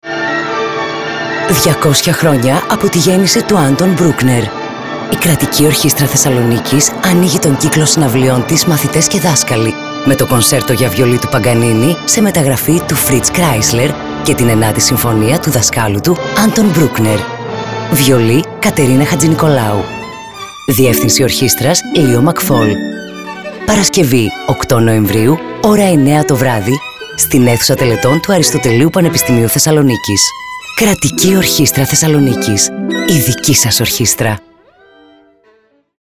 Ραδιοφωνικό σποτ 8νοε24_200 χρόνια από τη γέννηση του Anton Bruckner Κατηγορία: Ραδιοφωνικά σποτ Ηχος Ραδιοφωνικό σποτ 8νοε24_200 χρόνια Άντον Μπρούκνερ.mp3 Σχετικό με τις εξής εκδηλώσεις: 200 χρόνια από τη γέννηση του Anton Bruckner